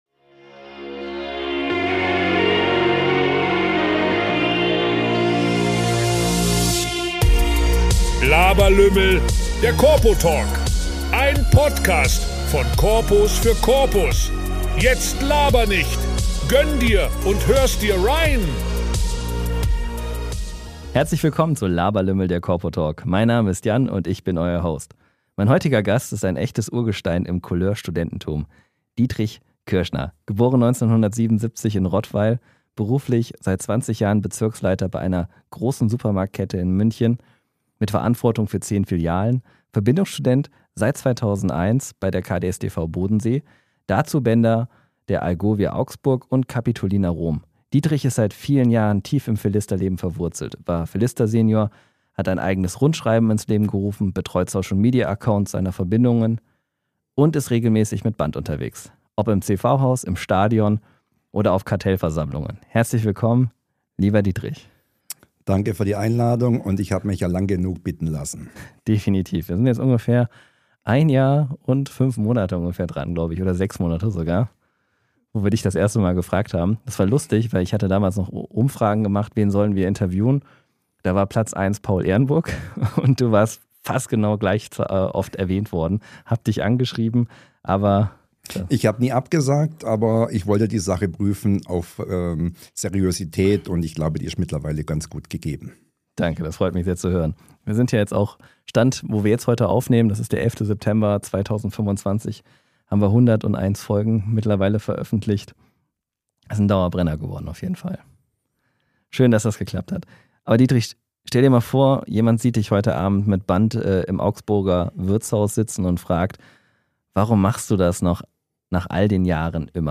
Ein Gespräch über Loyalität, Gemeinschaft und Brückenschläge zwischen Generationen.